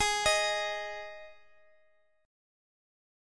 Listen to G#5 strummed